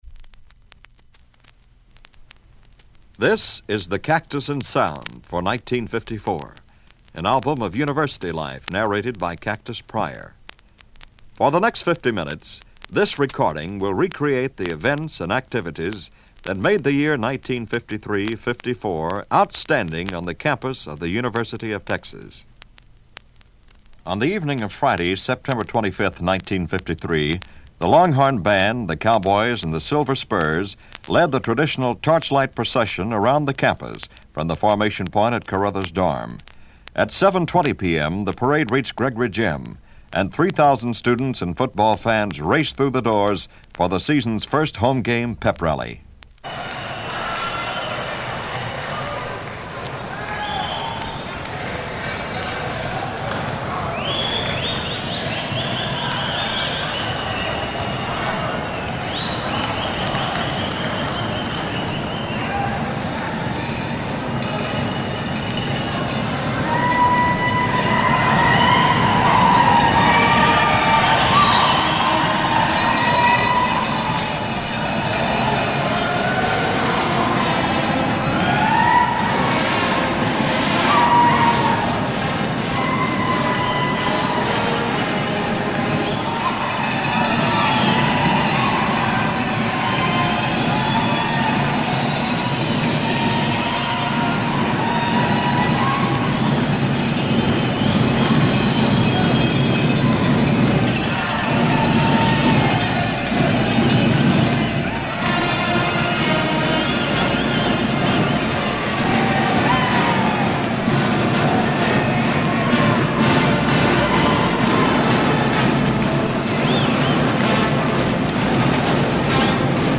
A football rally in Gregory Gym: September 25, 1953
Listen as the band enters the gym with the last strains of “Texas Fight,” before playing the “Texas Victory Song.”
A crowd of more than 3,000 – many ringing cowbells –  goes through several yells:
football-rally-gregory-gym-september-1953.mp3